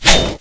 knife_slash2_hunter.wav